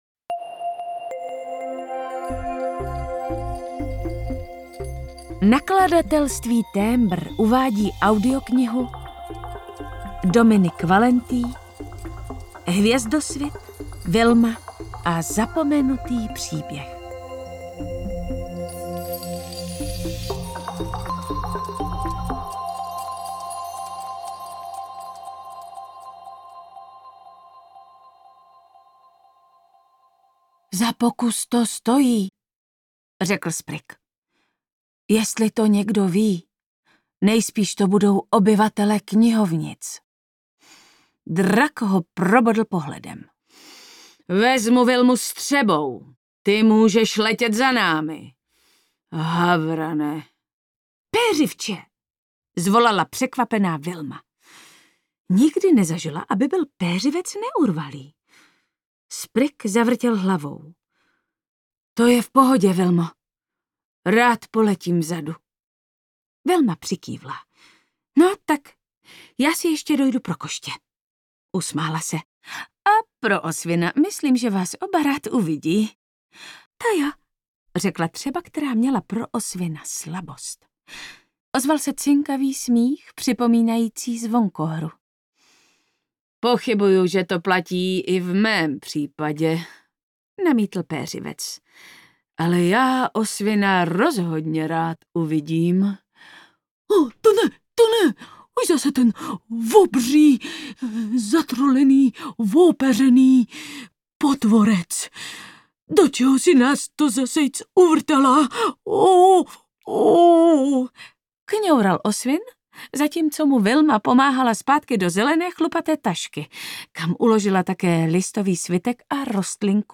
Vilma a zapomenutý příběh audiokniha
Ukázka z knihy